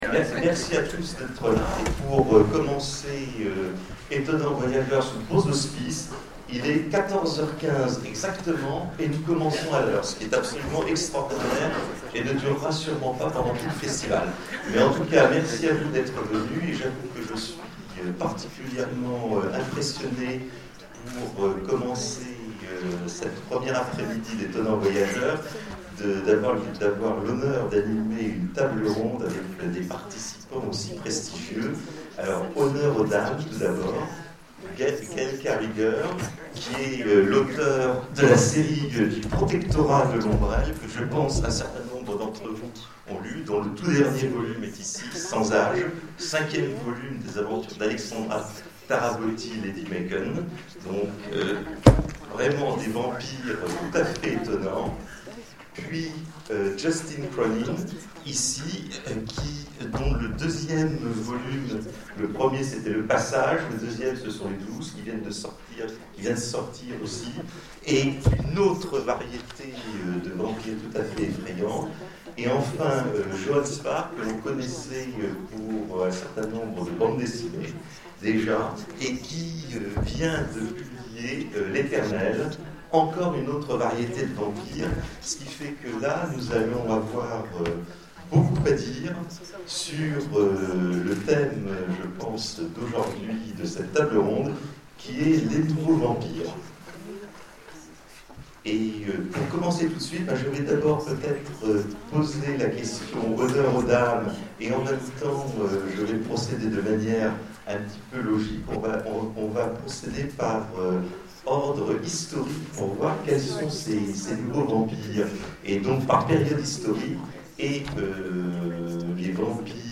Etonnants Voyageurs 2013 : Conférence Les nouveaux vampires